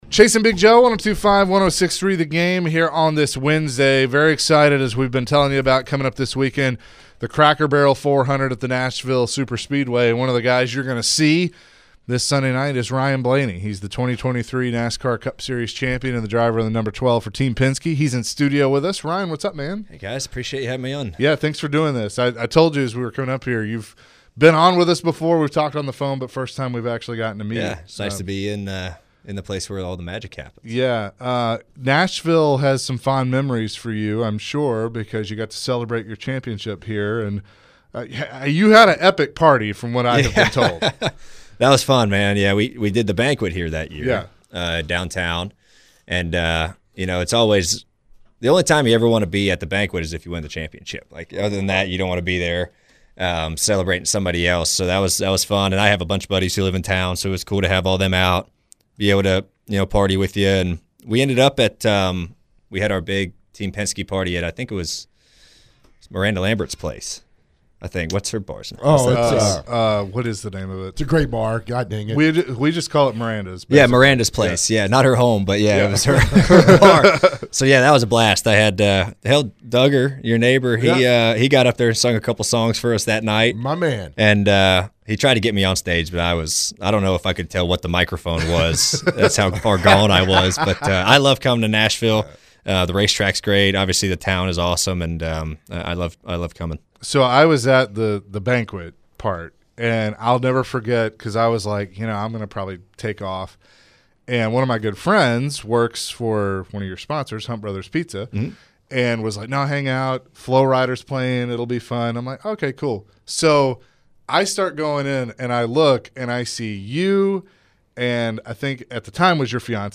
Ryan went through his preparation for the upcoming race in the Cracker Barrel 400. Later in the conversation, Ryan was asked about some interesting things while driving in a race and shared some laughs along the way.